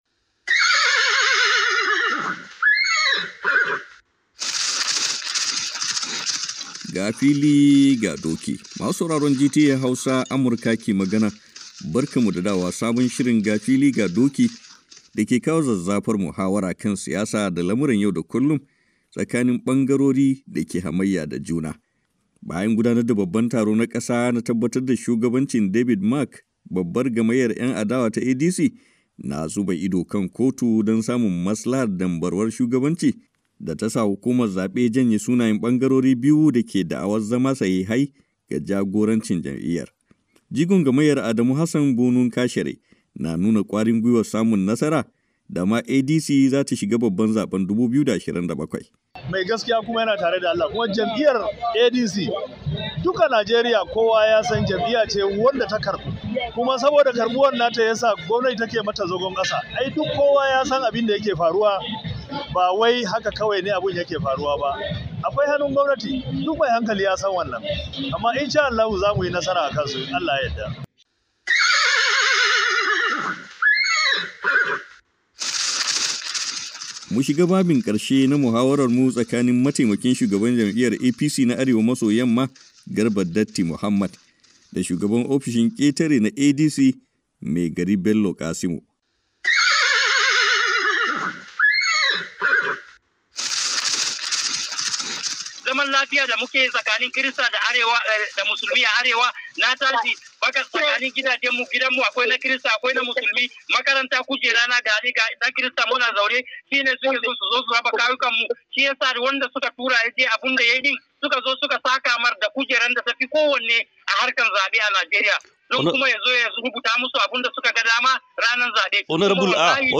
Masu sauraron GTA Hausa Amurka ke magana barkanmu da dawowa sabon shirin Ga Fili Ga Doki da ke kawo zazzafar muhawar kan siyasa da lamuran yau da kullum tsakanin bangarori da ke hamayya da juna.